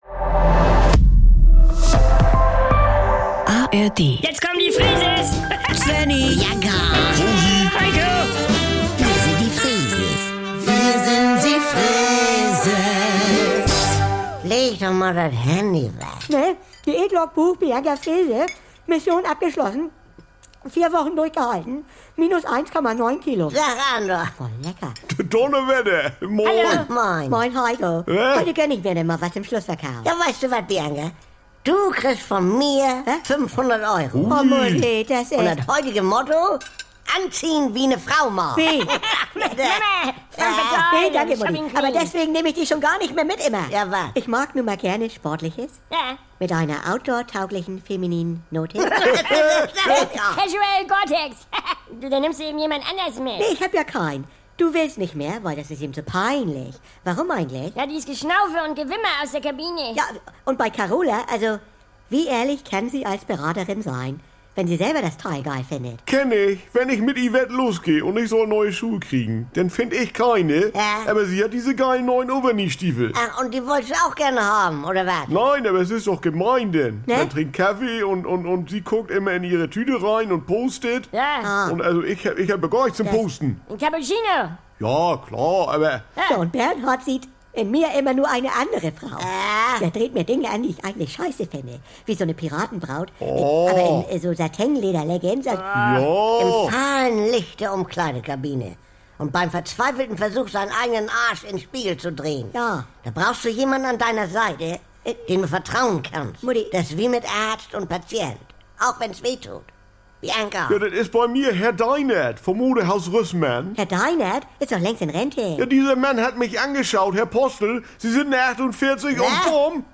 Jederzeit und so oft ihr wollt: Die NDR 2 Kult-Comedy